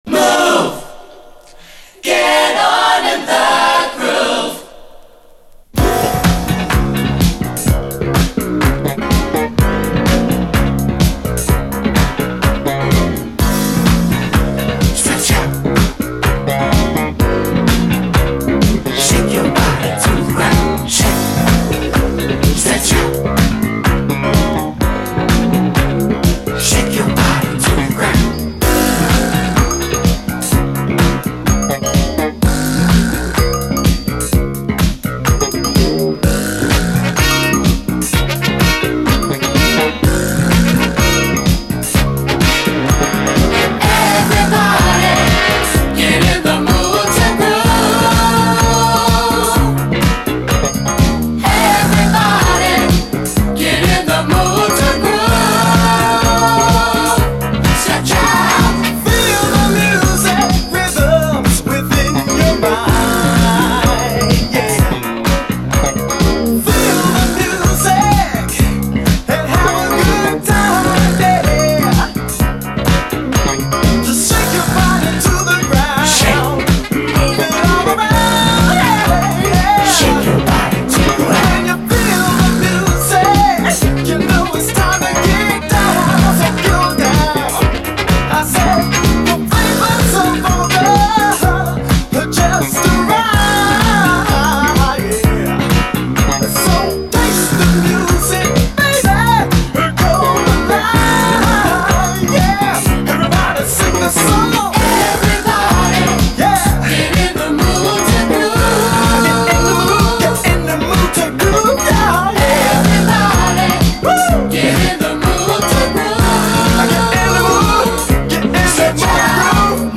SOUL, 70's～ SOUL, DISCO
ブリブリと強靭なベースが鈍い光を放ちながら突き進む！